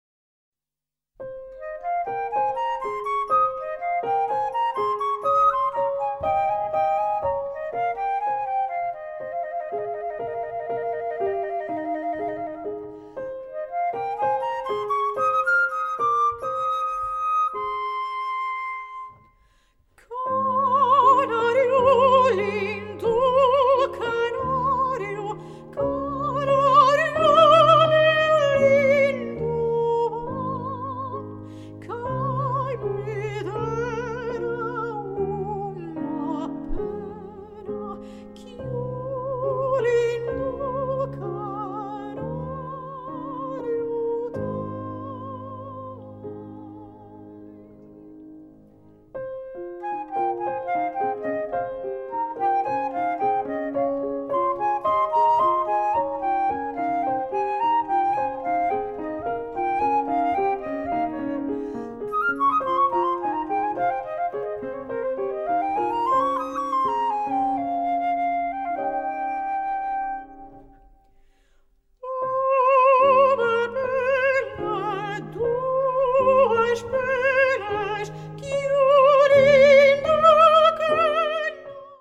Portuguese Soprano
PORTUGUESE SONGS